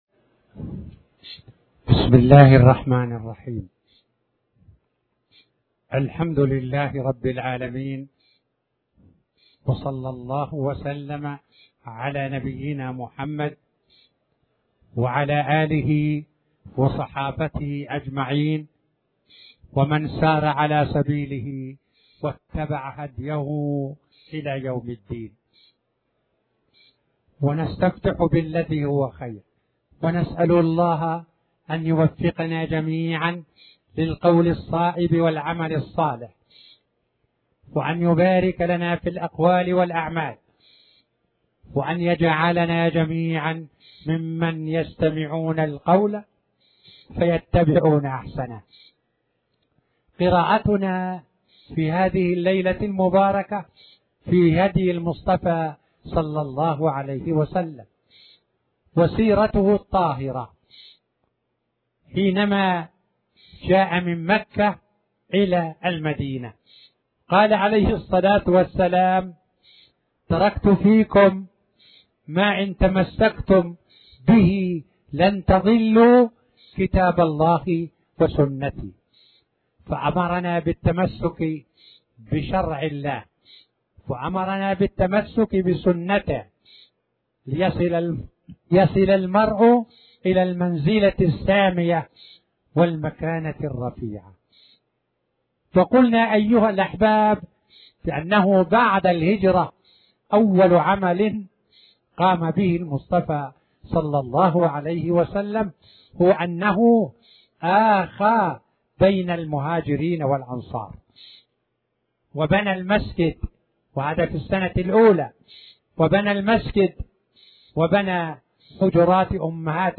تاريخ النشر ٤ ذو القعدة ١٤٣٩ هـ المكان: المسجد الحرام الشيخ